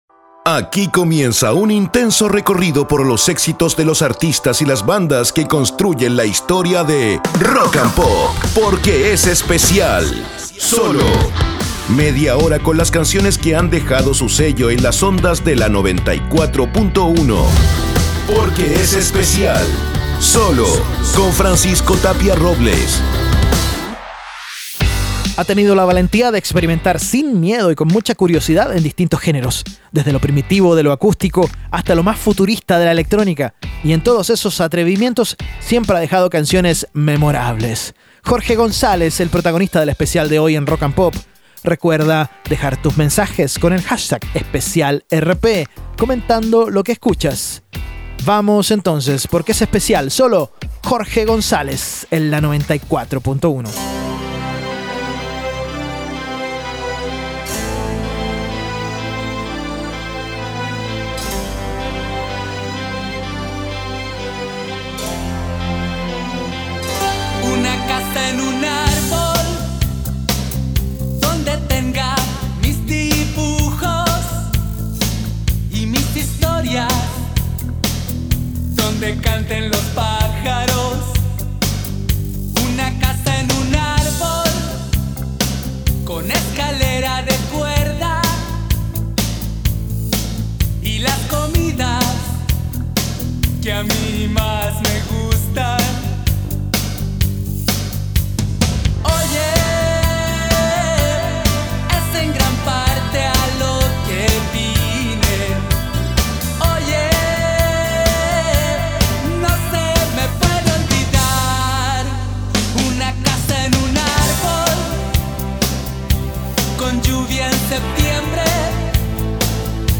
ROCK CHILENO